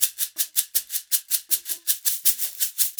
80 SHAK 01.wav